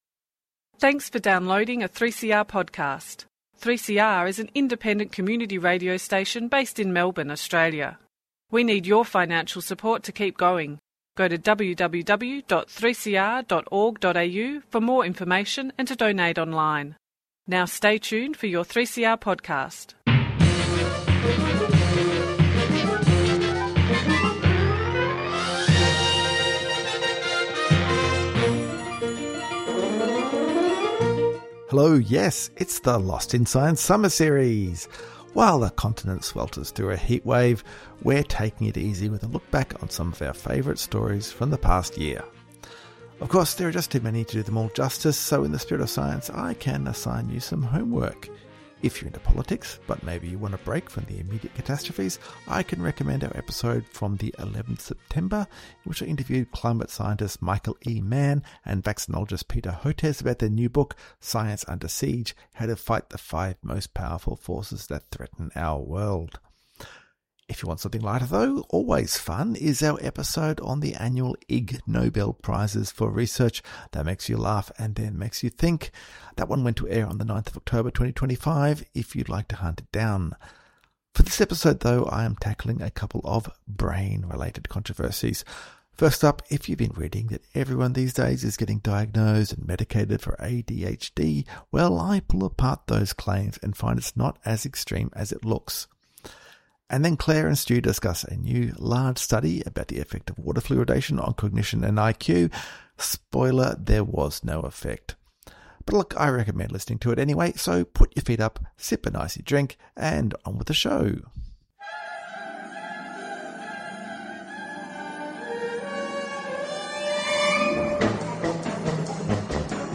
Tweet Lost in Science Thursday 8:30am to 9:00am Entertaining news and discussion about research that has impact on society and providing a wide range of science and technology news.